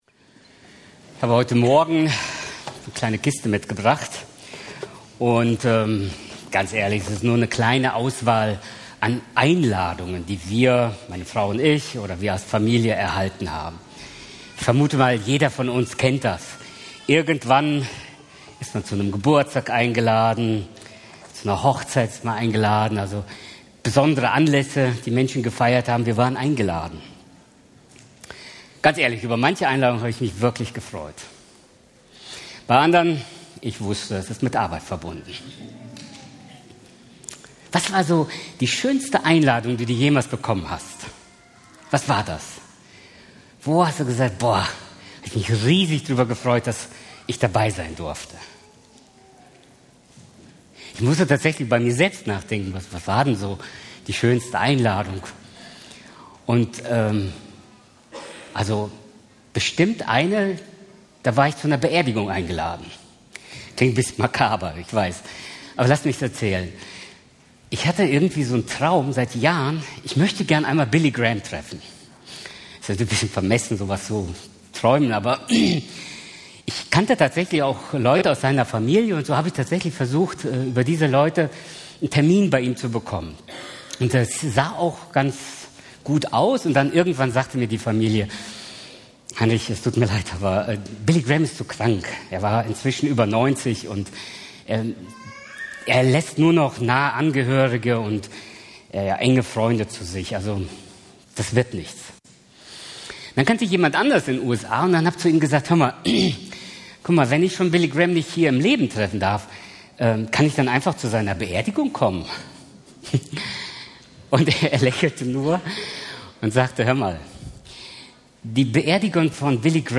Prediger